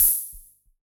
RDM_TapeB_SR88-OpHat.wav